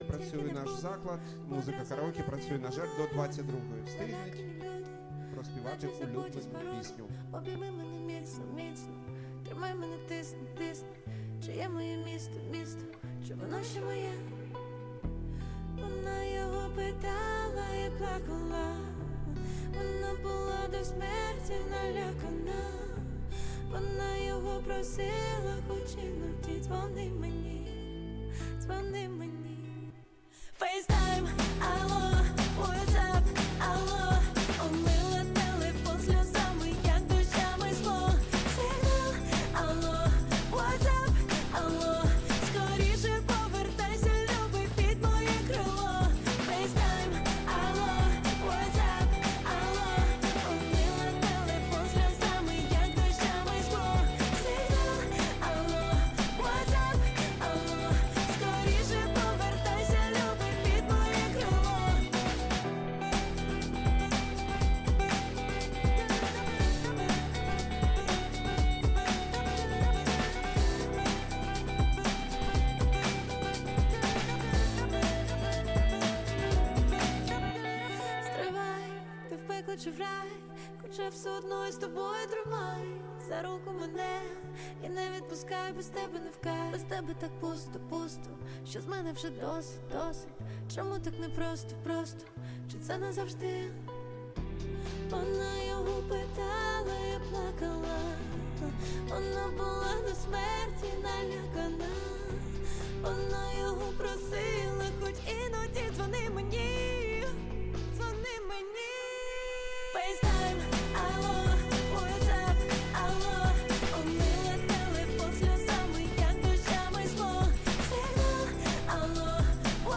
Караоке в Одесі, в Аркадії!
Постоянная ссылка URL (SEO) (SEO) Текущее время (SEO) Категория: Караоке вечори в Одесі Описание: Караоке в Одесі, в Аркадії - караоке-бар "PRINCE"!